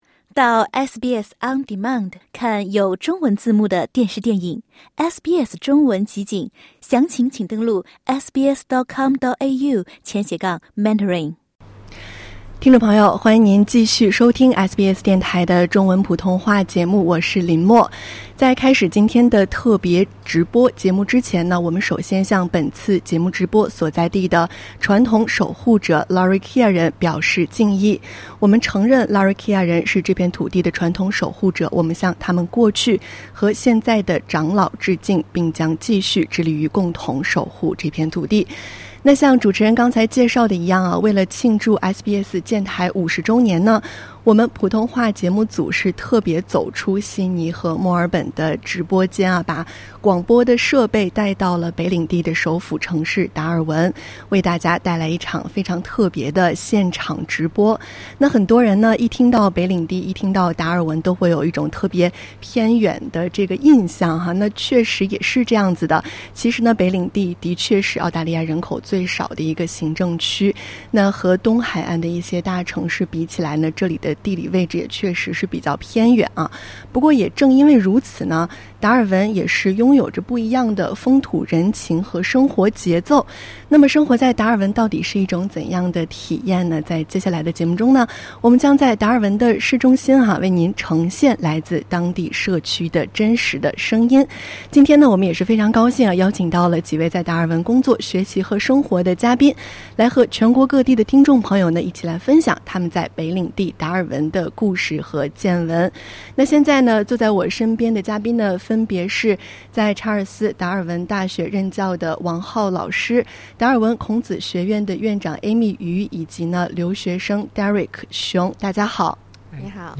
为庆祝SBS建台50周年，SBS普通话节目走出悉尼和墨尔本的演播室，来到了北领地的首府城市达尔文，为大家带来一场特别的现场直播。
点击音频，收听记者从达尔文带来的现场报道。